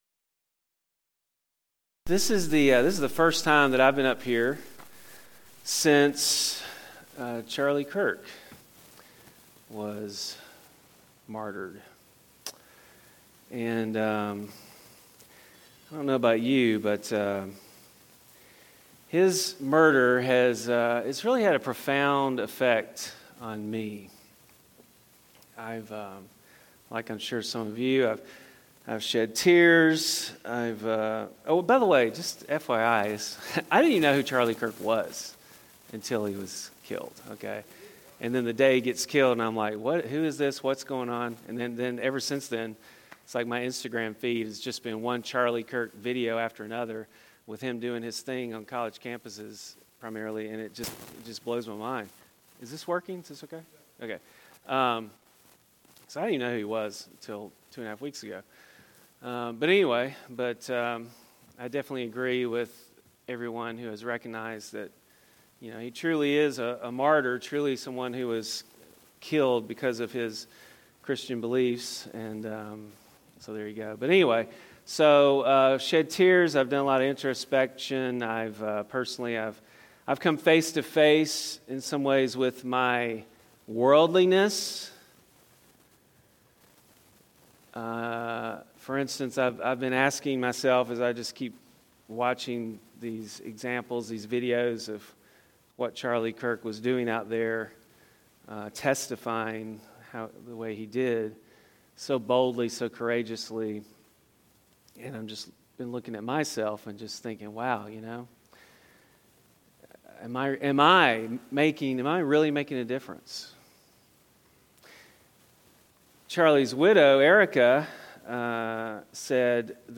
A message from the series "Ironworks."